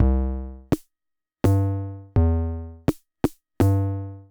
レゲエ界に革命を起こしたリズムパターン「スレンテン」で知られる電子キーボード MT-40
◆Waltz